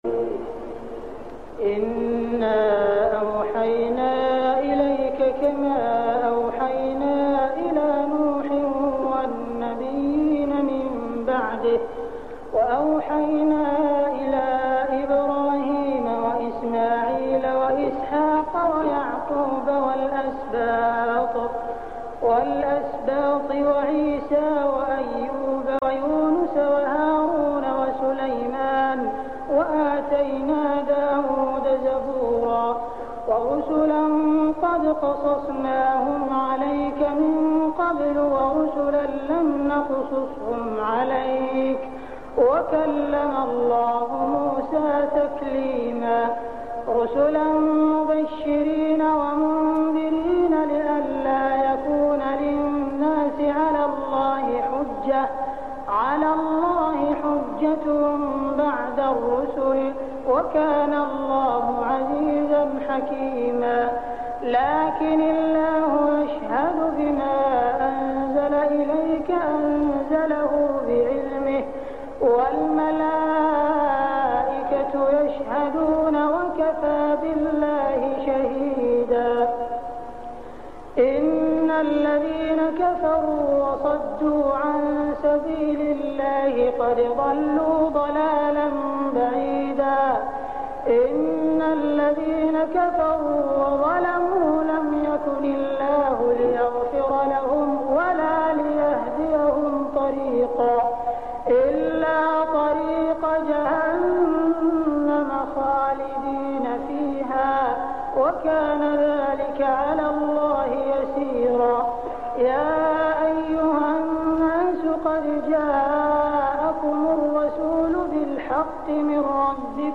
صلاة التراويح ليلة 7-9-1407هـ سورتي النساء 163-176 و المائدة 1-40 | Tarawih Prayer Surah An-Nisa and Al-Ma'idah > تراويح الحرم المكي عام 1407 🕋 > التراويح - تلاوات الحرمين